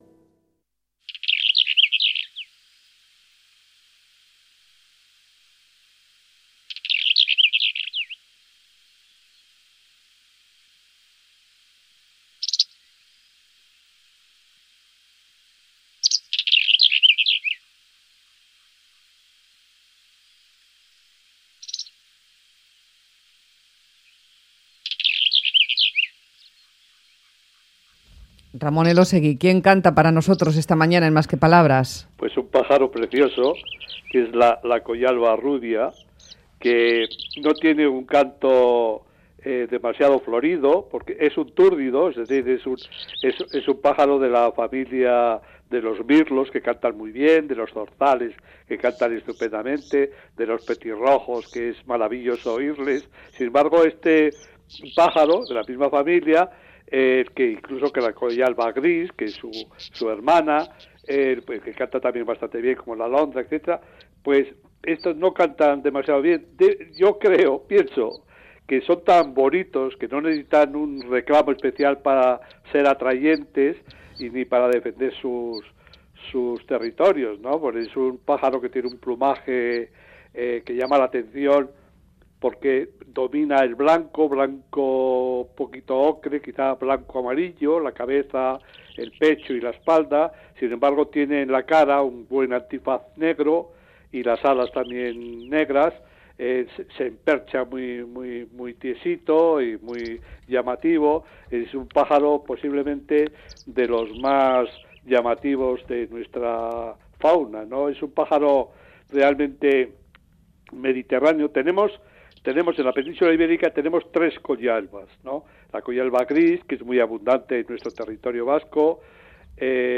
La Collalba rubia